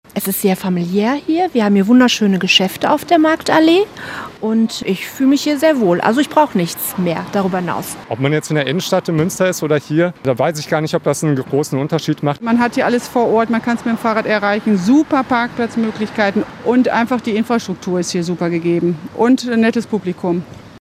Wir haben euch in Hiltrup besucht und gefragt, was für euch den Stadtteil so schön macht:
Umfrage: Was ist an Hiltrup toll?